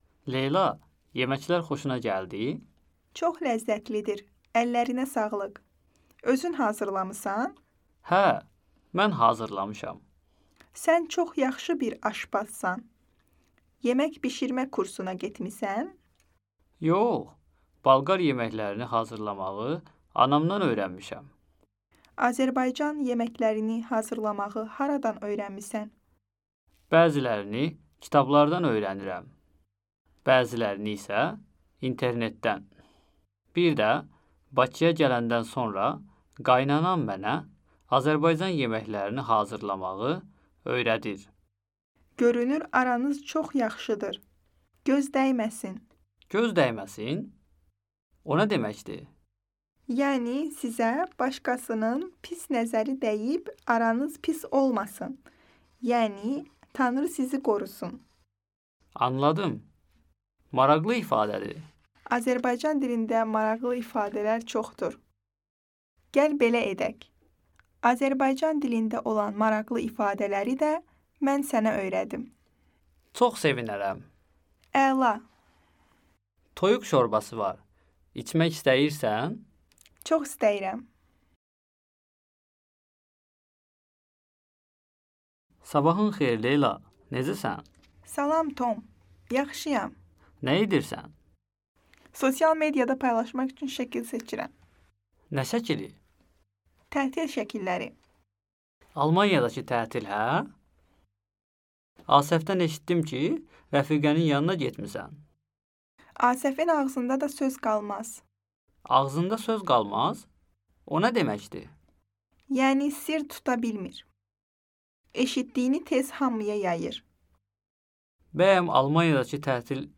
アゼルバイジャン語の慣用句を使った会話や決済時の会話を学びます。